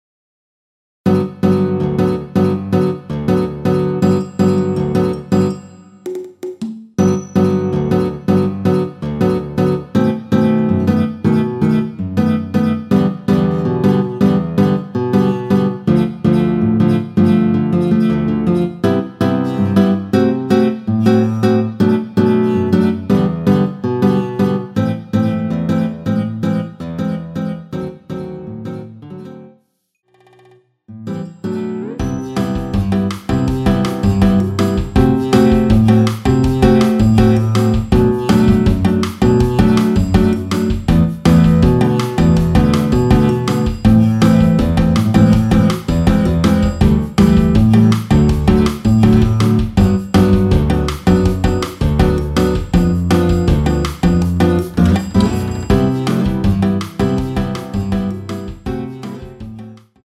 ◈ 곡명 옆 (-1)은 반음 내림, (+1)은 반음 올림 입니다.
앞부분30초, 뒷부분30초씩 편집해서 올려 드리고 있습니다.
중간에 음이 끈어지고 다시 나오는 이유는
위처럼 미리듣기를 만들어서 그렇습니다.